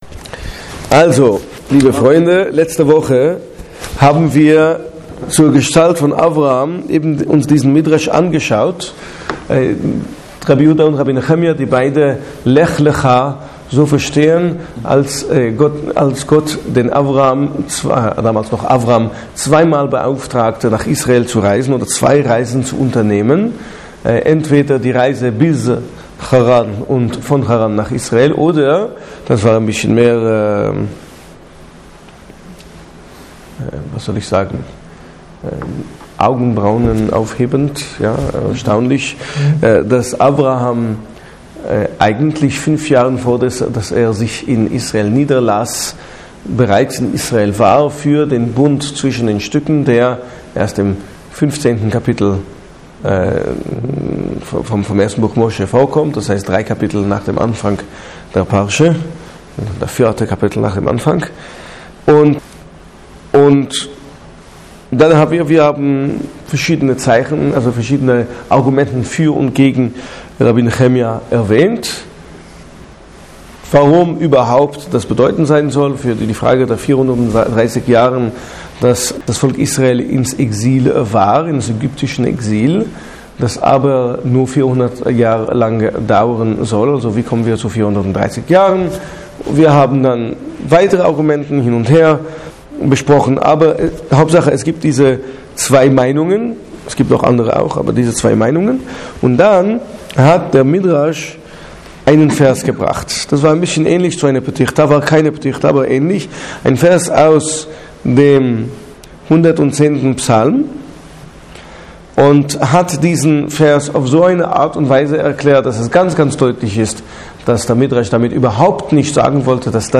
Zur Vortrags-Serie: Obwohl er eine der bedeutendsten biblischen Persönlichkeiten ist, erzählt die Torá immerhin nur einige ausgewählte Kapitel des Lebens Abrahams.